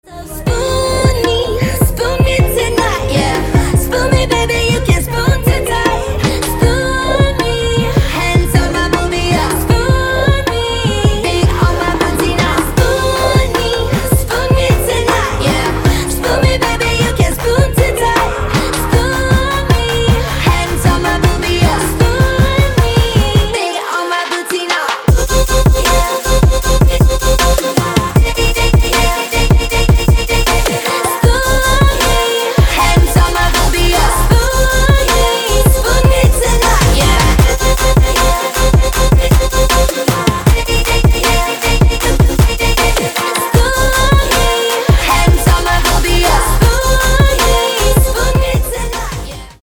• Качество: 160, Stereo
женский вокал
dance
club
танцевальные